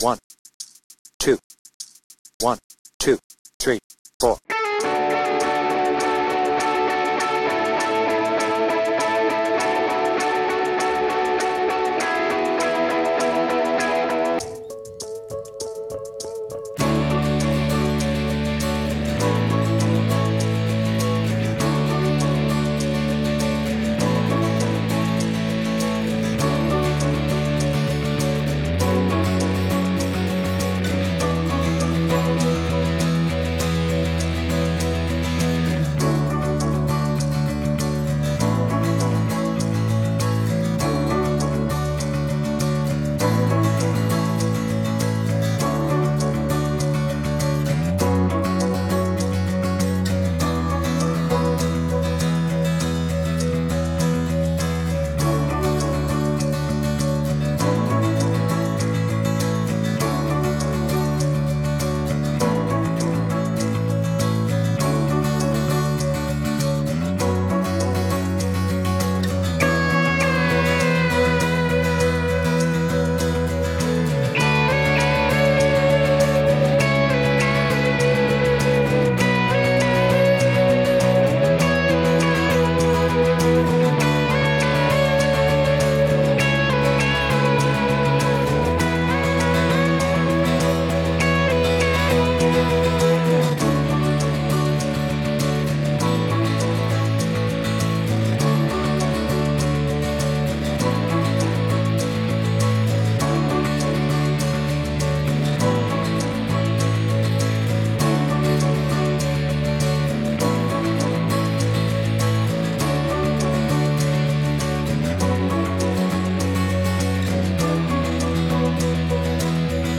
Tuning : E
Without vocals